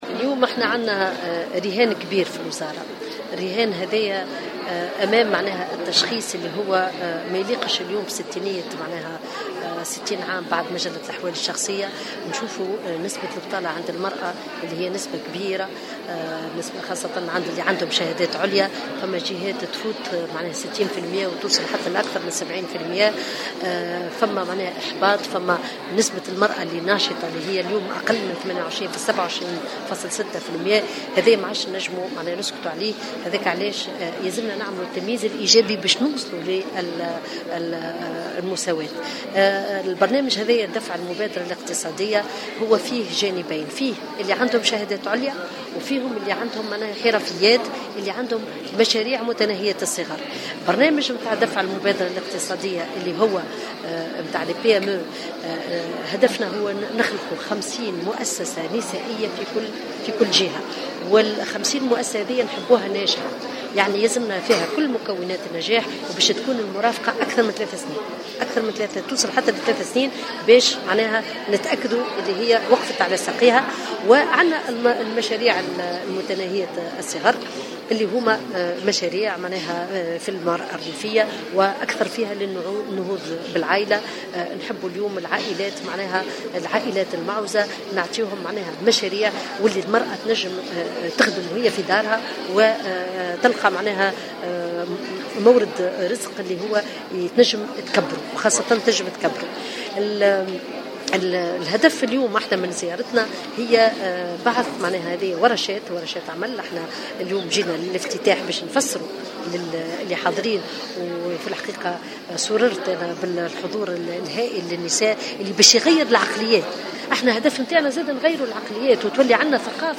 وأكدت مرعي في تصريح للجوهرة أف أم خلال هذه الزيارة، أن الوزارة أطلقت مشروع دفع المبادرة الاقتصادية بهدف الوصول إلى بعث 50 مؤسسة نسائية في كل جهة، توفر لها الوزارة الإحاطة الضرورية لفترة قد تصل إلى 3 سنوات بما من شأنه أن يعزز من حظوظ هذه المؤسسات في النجاح.